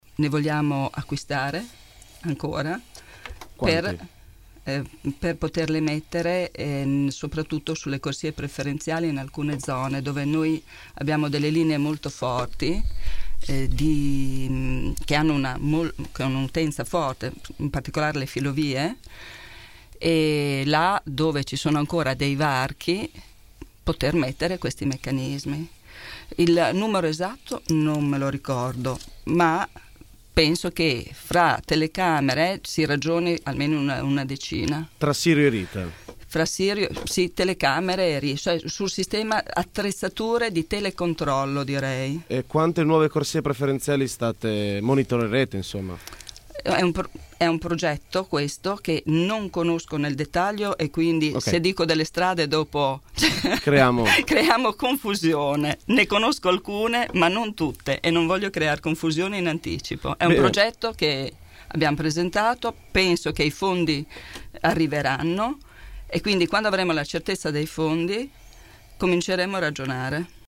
“C’è l’obiettivo di aumentarle perché i mezzi pubblici possano viaggiare più velocemente” ha fatto sapere questa mattina durante il microfono aperto nei nostri studi l’assessore al Traffico e alla Mobilità, Simonetta Saliera.